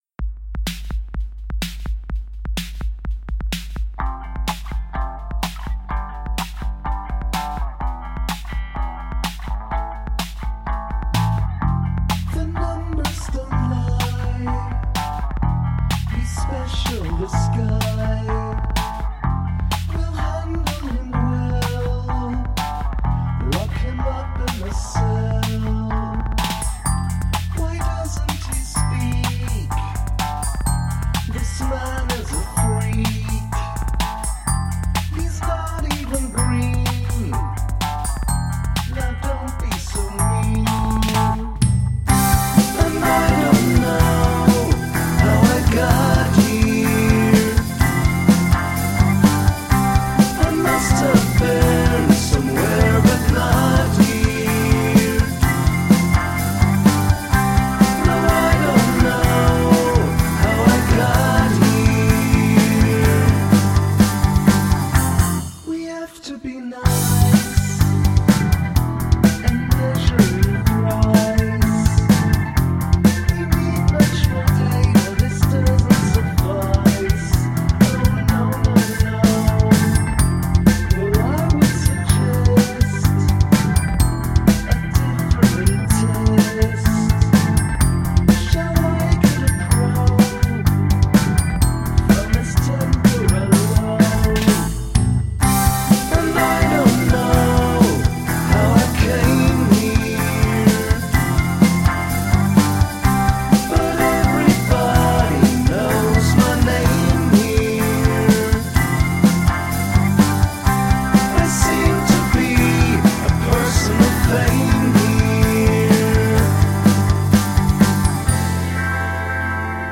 Use the Royal Road chord progression
It's kind of an unusual mixture of styles.
Nice leads and bgv.